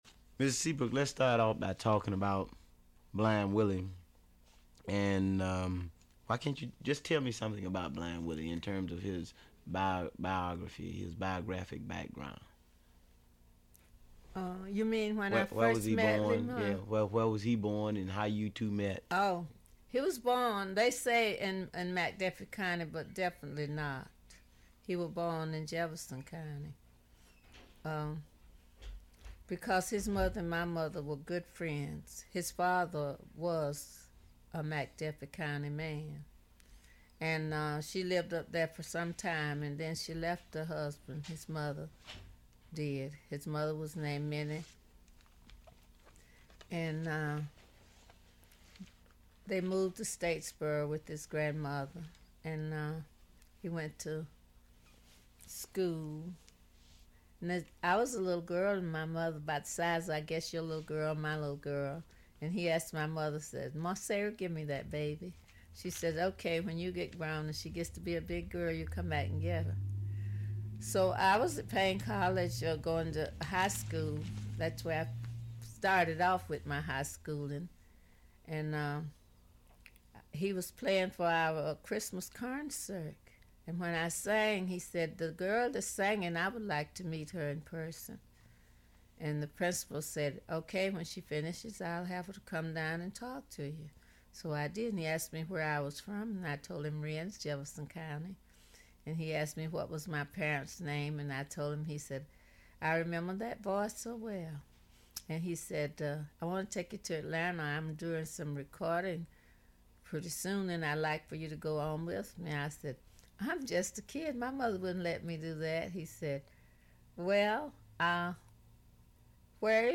Radio Free Georgia has even partnered with Atlanta History Center to record a fascinating series of oral histories that detail the experiences of Atlantans great and small between the first and second World Wars.